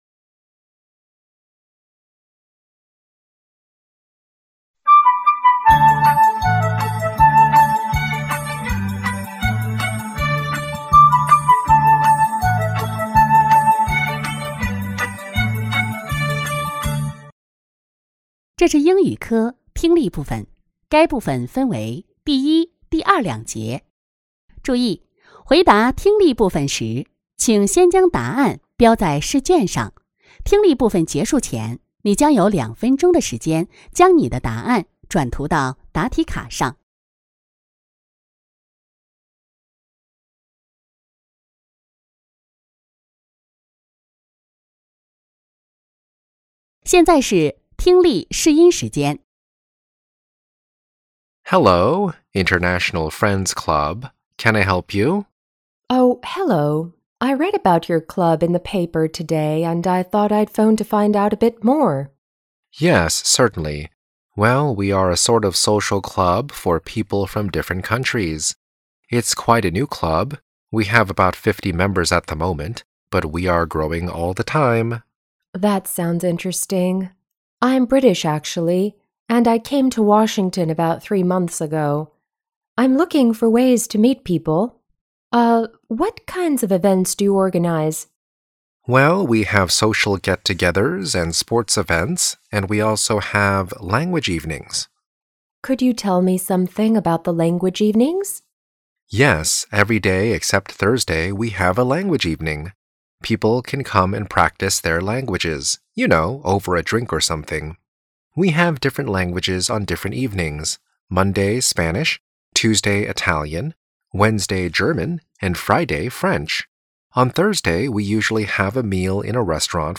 2023届四川九市联考二诊英语听力.mp3